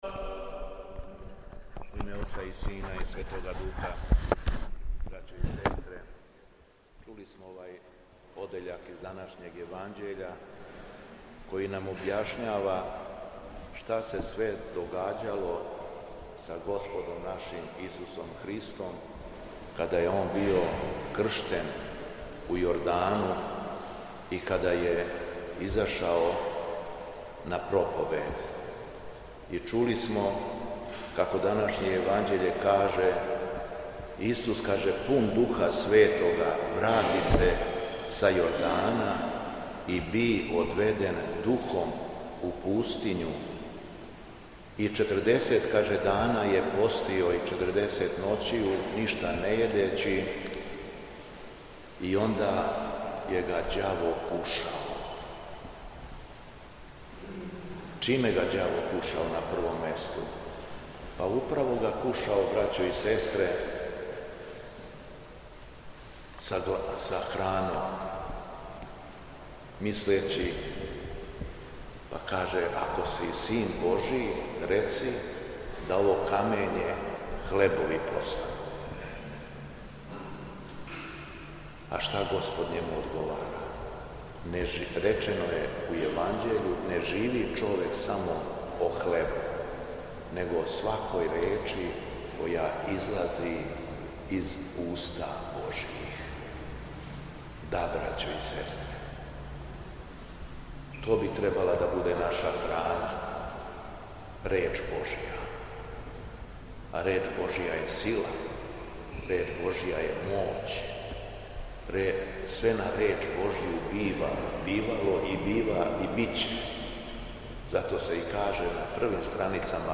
Његово Високопреосвештенство Митрополит шумадијски Господин Јован служио је Свету Литургију у Саборном храму Успењ...
Беседа Његовог Високопреосвештенства Митрополита шумадијског г. Јована